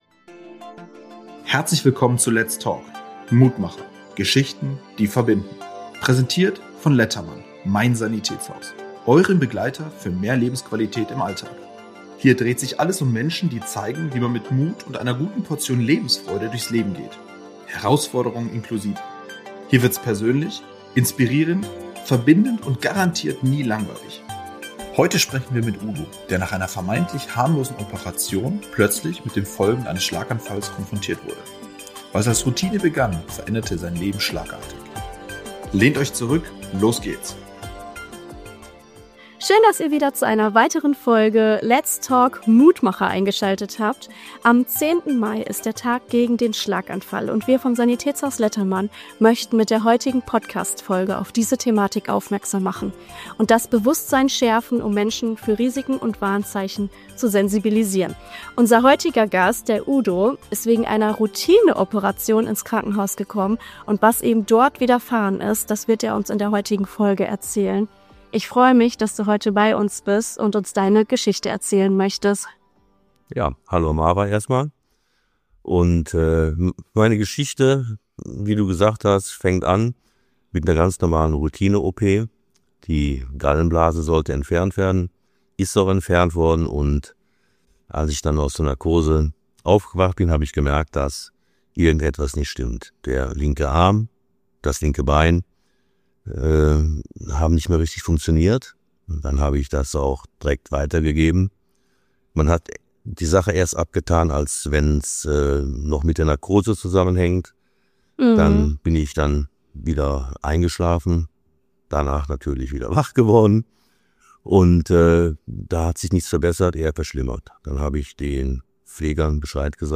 Ein bewegendes Gespräch über Akzeptanz, neue Wege und den Mut, das Leben neu zu gestalten.